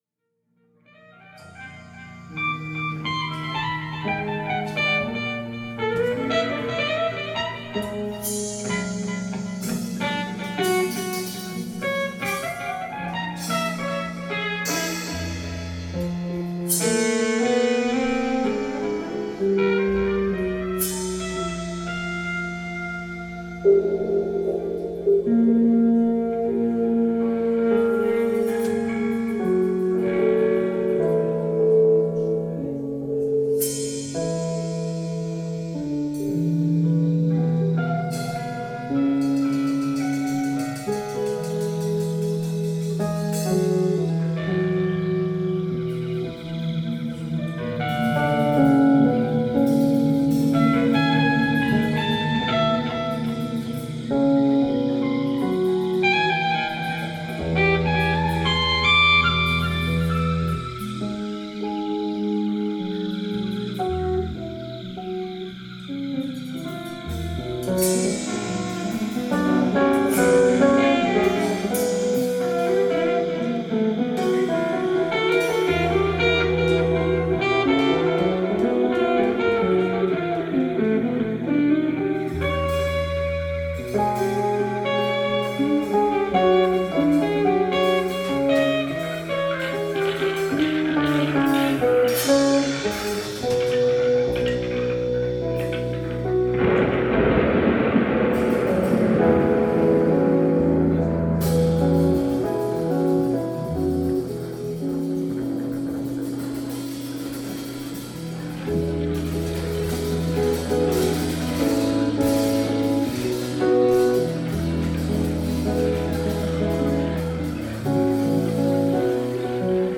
live bei der Jazznacht Soest am 07.02.2026
Keyboard/Synthesizer
Flügelhorn/E-Gitarre
E-Gitarre
Bass/Samples
Schlagzeug